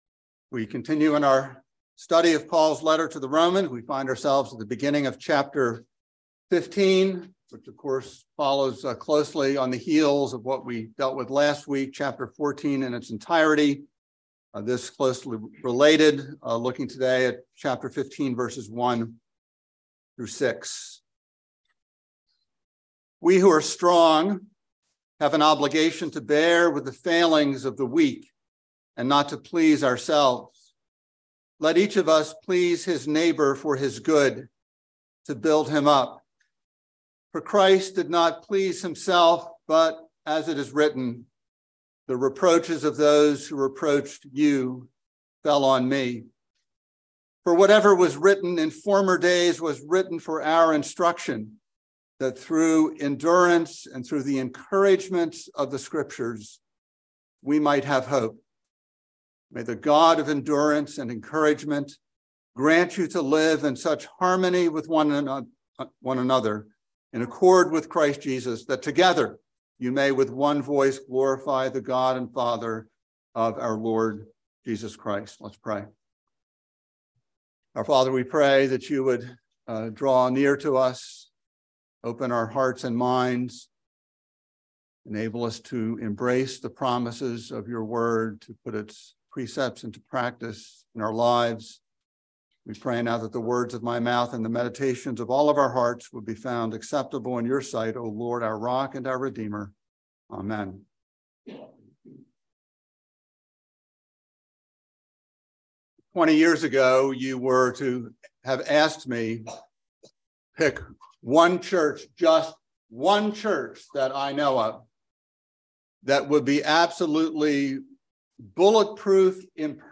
by Trinity Presbyterian Church | Apr 12, 2023 | Sermon